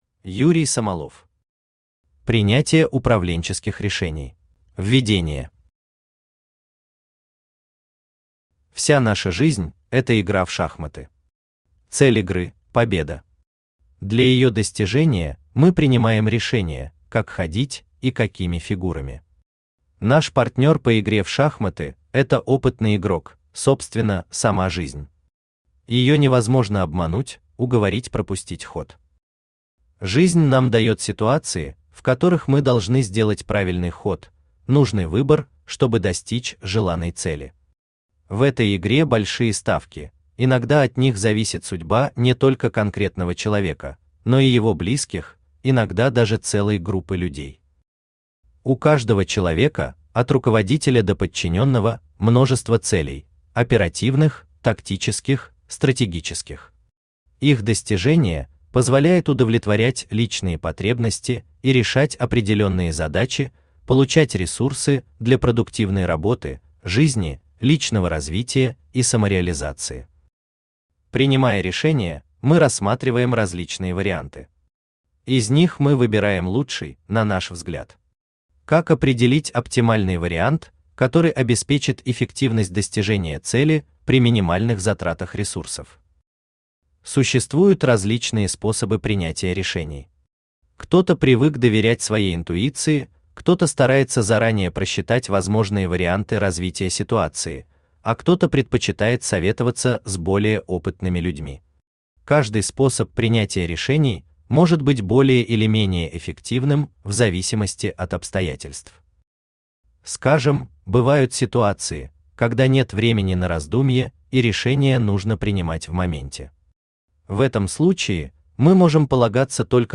Аудиокнига Принятие управленческих решений | Библиотека аудиокниг
Aудиокнига Принятие управленческих решений Автор Юрий Самолов Читает аудиокнигу Авточтец ЛитРес.